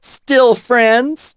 poco_kill_03.wav